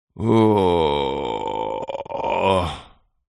Звуки похмелья
Звук утреннего пробуждения с похмельем